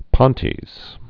(pŏntēz)